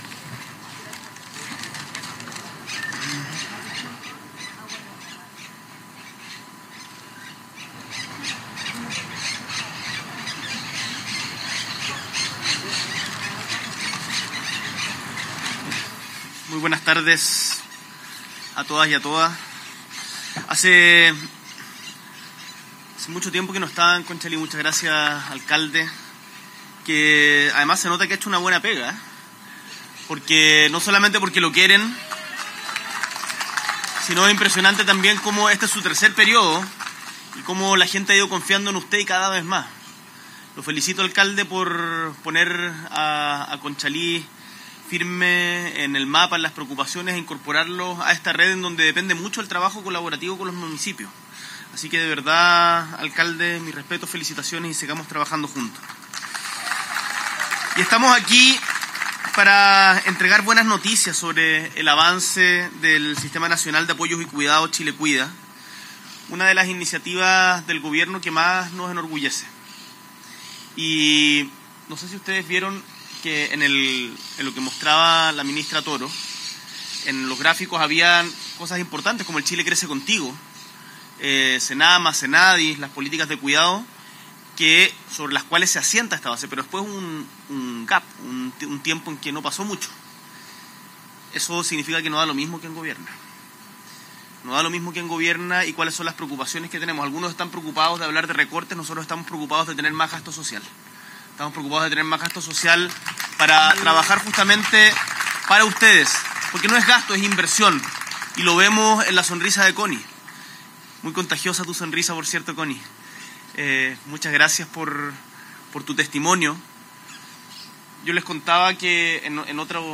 Audio Discurso